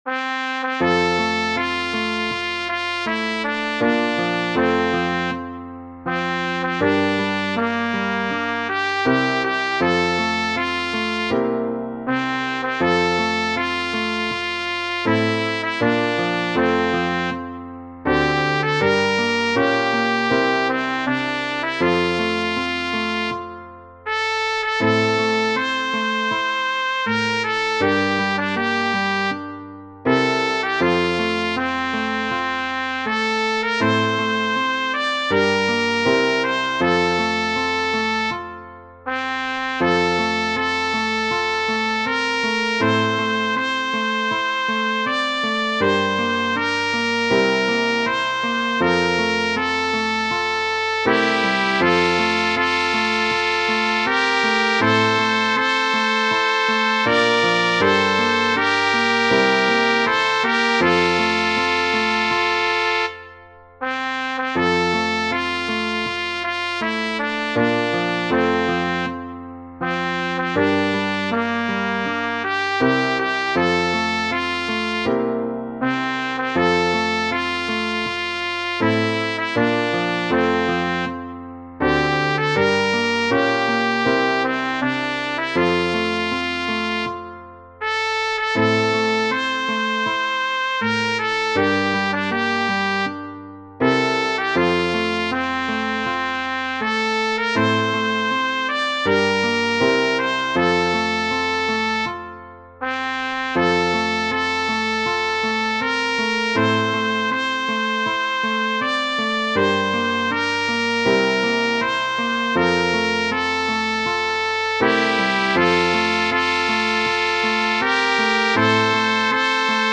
Tradizionale Genere: Religiose O Maria,quanto sei bella, sei la gioia e sei l’amore, m’hai rapito questo cuore, notte e giorno io penso a Te.